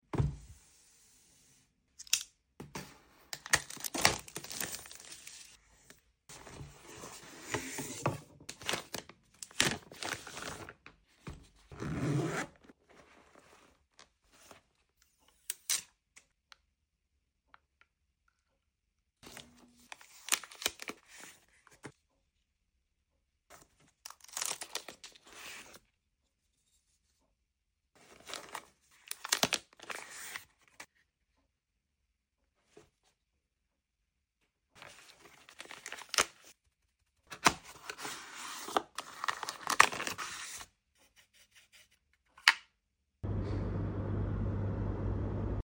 Some soothing unboxing sounds for sound effects free download